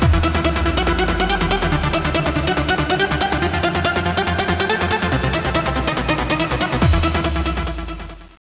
techno song